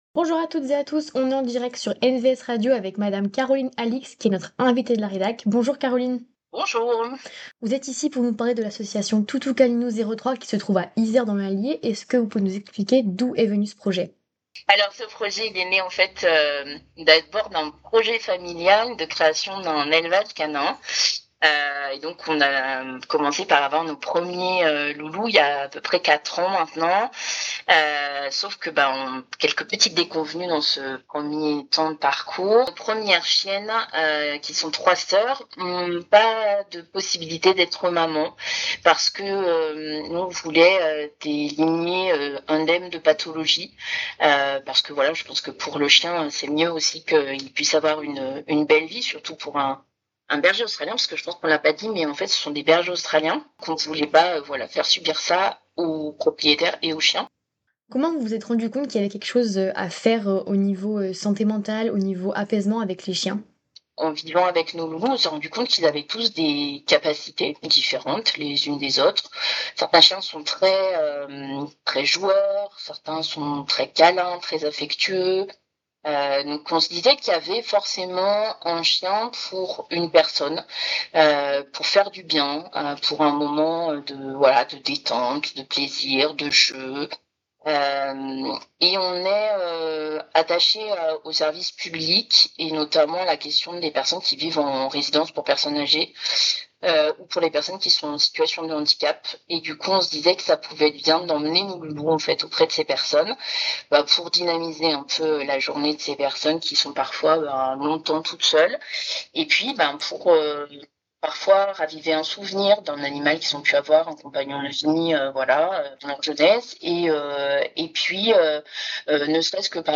Jeudi 26 février, l’association Toutous Câlinous 03 était à notre micro.